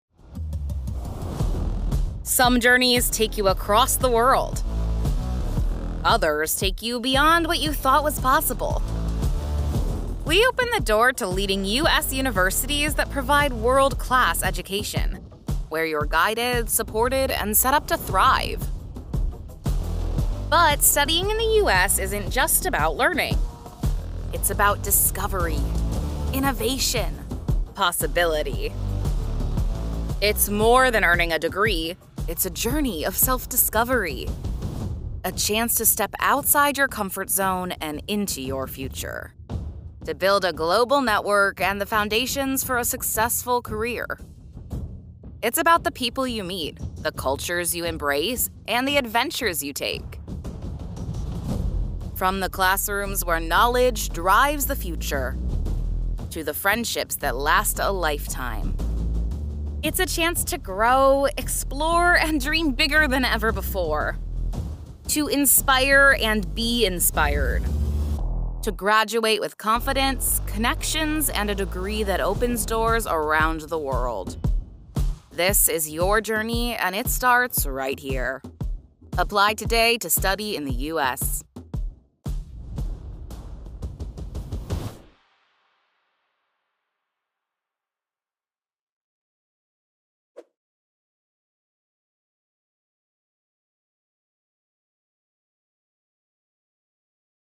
Female
Yng Adult (18-29), Adult (30-50)
My voice is warm, friendly, and bright.
Narration
Study Abroad Video Narration